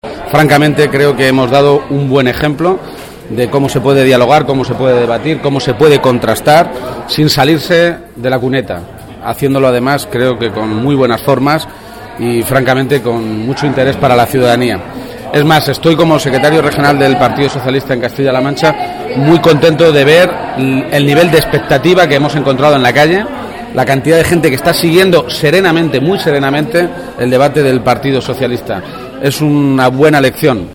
García-Page realizó estas declaraciones después de votar en la sede del PSOE de Toledo donde estuvo acompañado por su madre